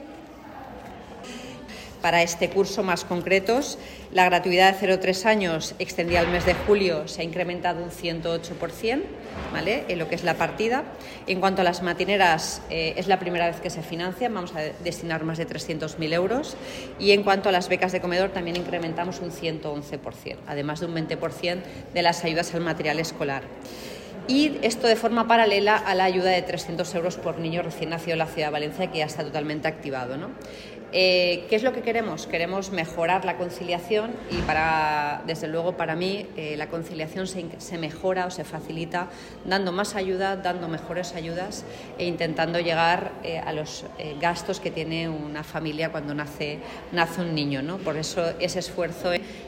• La alcaldesa, durante la visita en la Escuela Infantil Municipal Solc este lunes